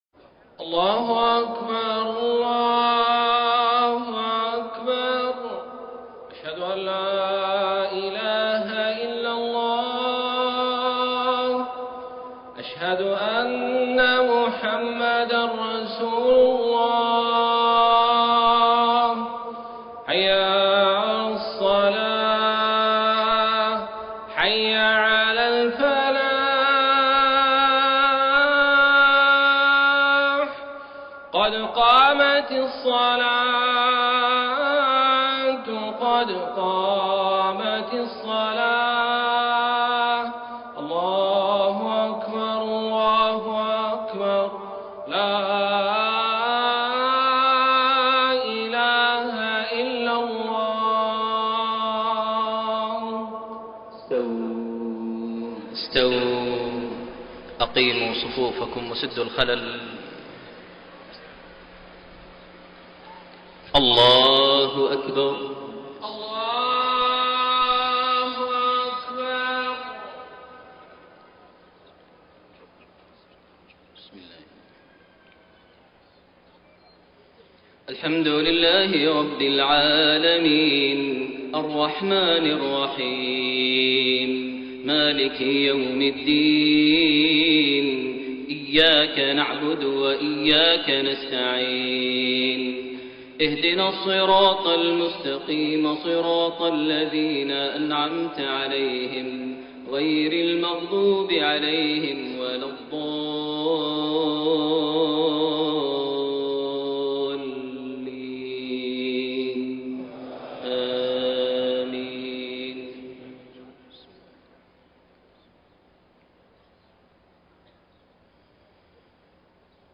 صلاة المغرب 21 جمادى الأولى 1433هـ سورتي الانفطار و الطارق > 1433 هـ > الفروض - تلاوات ماهر المعيقلي